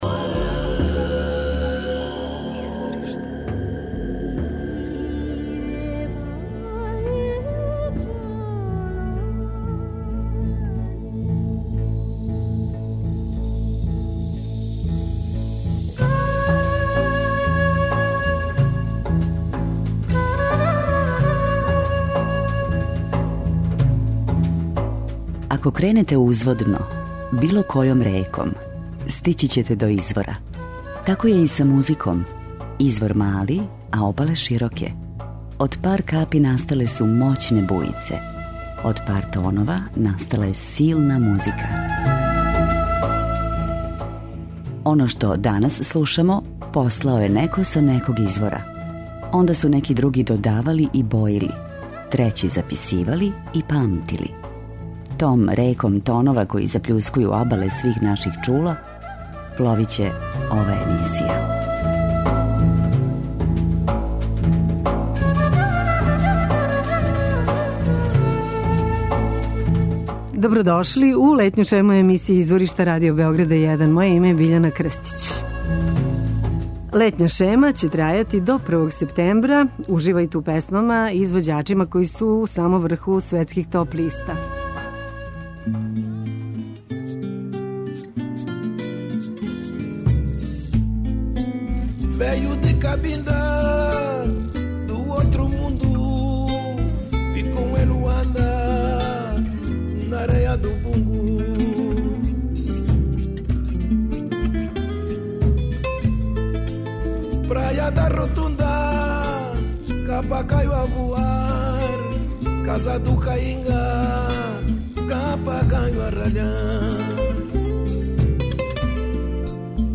Они који још увек нису отпутовали или остају преко лета у Београду моћи ће да уживају у врелим ритмовима и мелодијама из читавог света које смо одабрали у летњој шеми емисије Изворишта.
преузми : 28.66 MB Изворишта Autor: Музичка редакција Првог програма Радио Београда Музика удаљених крајева планете, модерна извођења традиционалних мелодија и песама, културна баштина најмузикалнијих народа света, врели ритмови... У две речи: World Music.